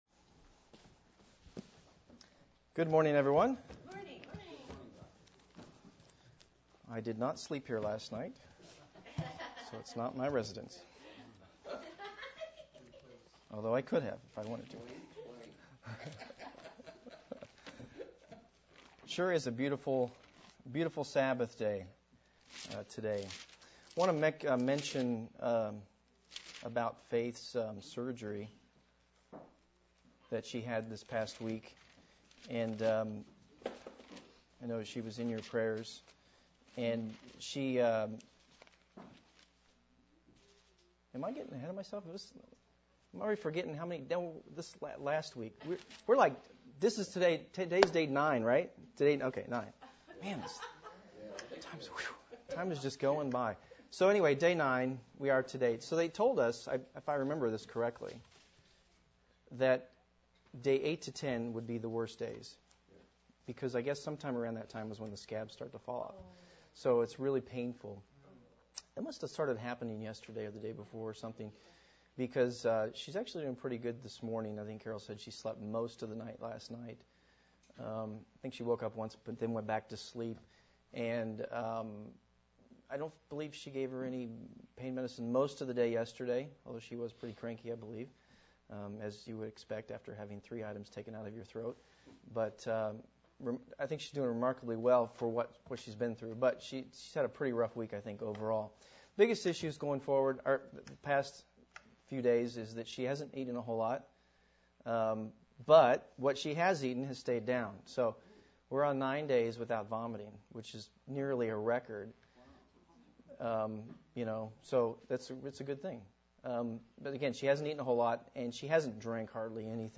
Sermon: Marriage is a model of Christ and the Church.